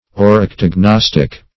-- Or`yc*tog*nos"tic , a. -- Or`yc*tog*nos"tic*al , a. [Obs.]